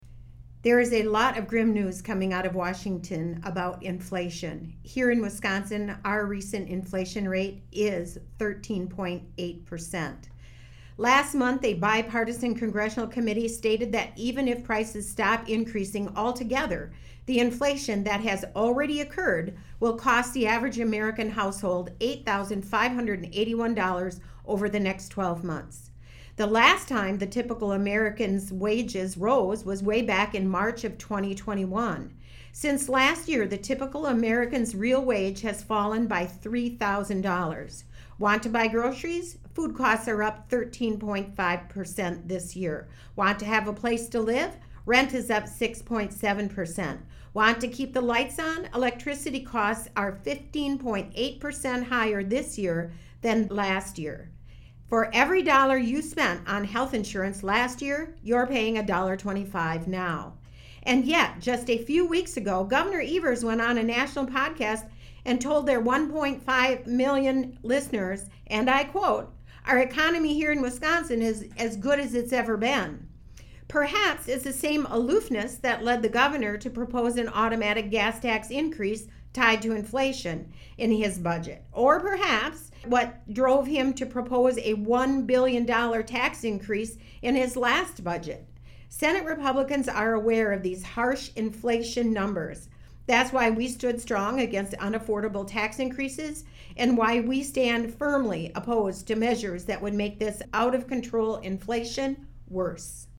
Weekly GOP radio address: Sen. Kathy Bernier attacks Gov. Evers' proposed tax increases, saying inflation is already costing Americans too much - WisPolitics